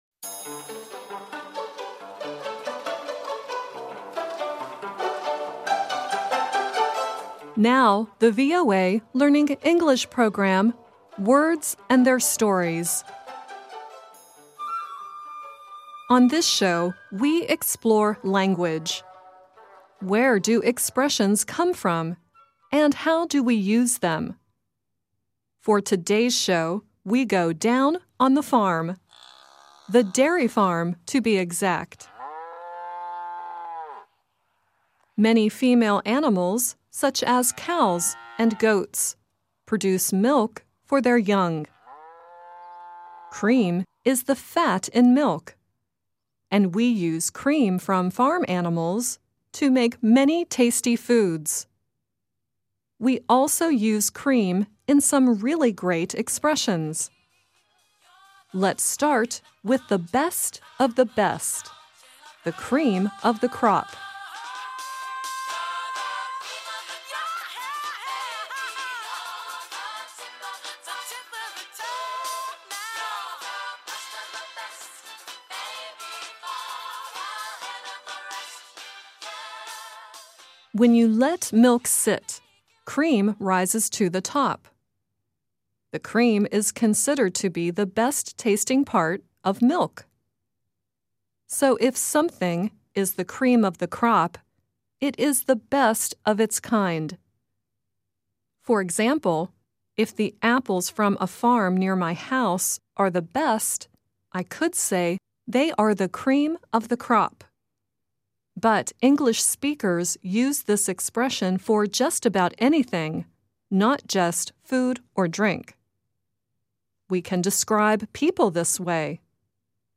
The song at the end is Tommy Tune singing “It’s Not Where You Start, It’s Where You Finish,” from the musical Seesaw.